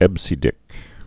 (ĕbsē-dĭk)